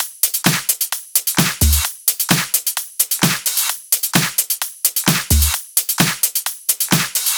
VDE 130BPM Change Drums 2.wav